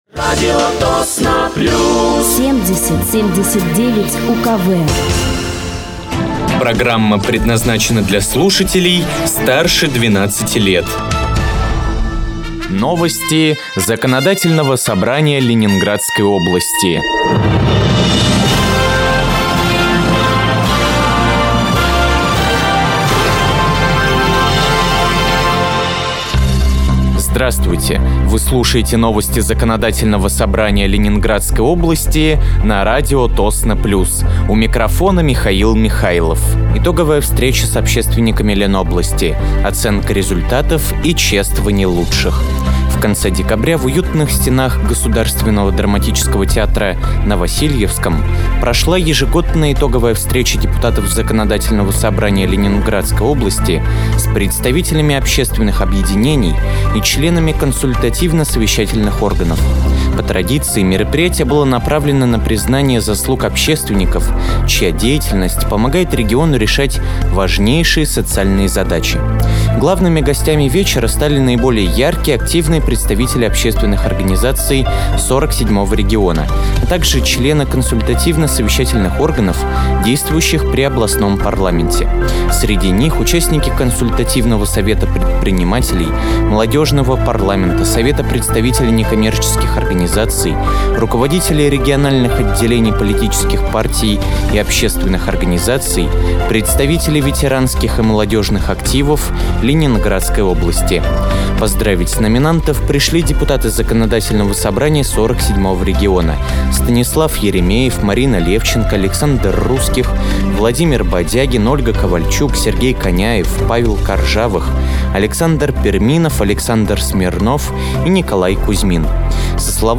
Вы слушаете новости Законодательного собрания Ленинградской области от 23.01.2025 на радиоканале «Радио Тосно плюс».